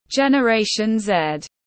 Thế hệ Gen Z tiếng anh gọi là generation Z, phiên âm tiếng anh đọc là /dʒen.əˌreɪ.ʃən ˈzed/.
Generation Z /dʒen.əˌreɪ.ʃən ˈzed/